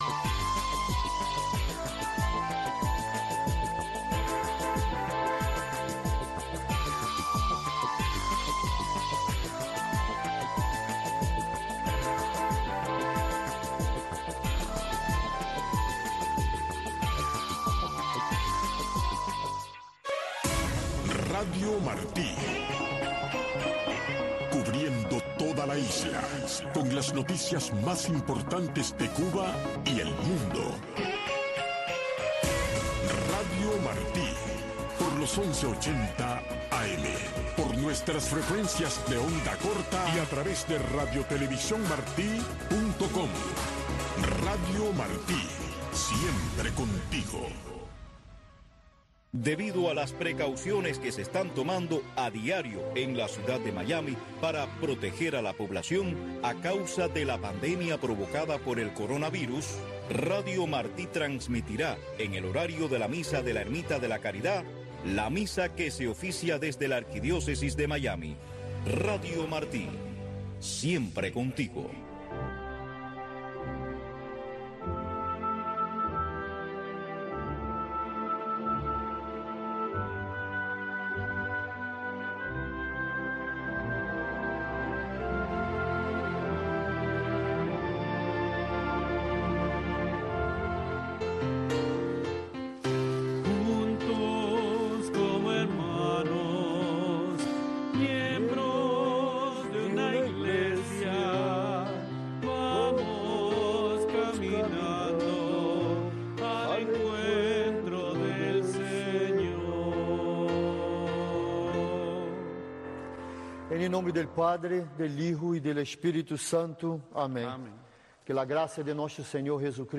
La Santa Misa
El Santuario Nacional de Nuestra Señor de la Caridad, más conocido como la Ermita de la Caridad, es un templo católico de la Arquidiócesis de Miami dedicado a Nuestra Señora de la Caridad, Patrona de Cuba.